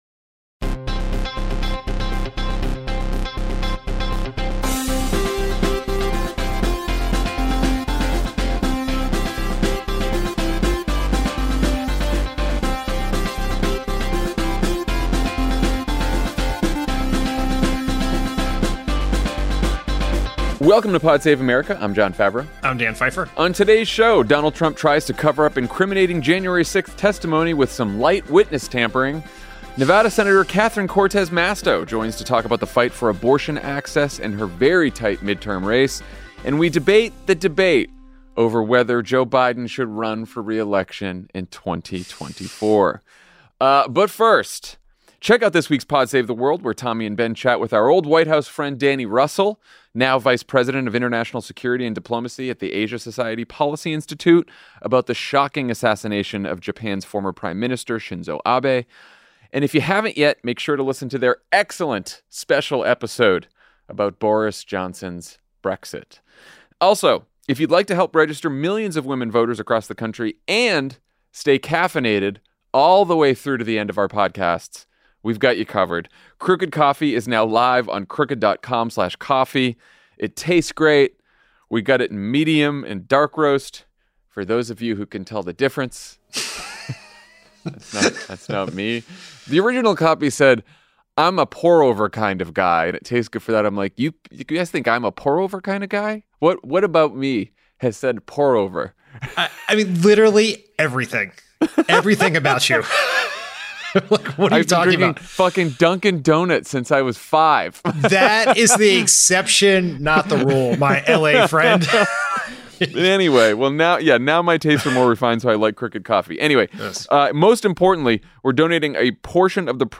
Donald Trump tries to cover up incriminating January 6th testimony with some light witness tampering, Nevada Senator Catherine Cortez Masto joins to talk about the fight for abortion access and her very tight midterm race, and Jon and Dan debate the debate over whether Joe Biden should run for re-election in 2024.